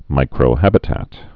(mīkrō-hăbĭ-tăt)